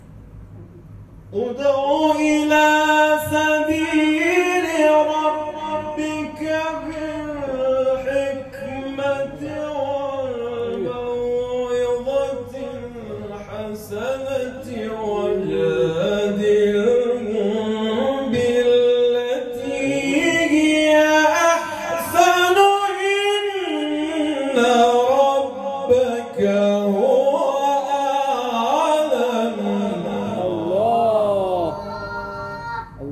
جلسه بانشاط آموزش قرآن در محله افسریه + عکس و صوت
جلسه قرآن محله افسریه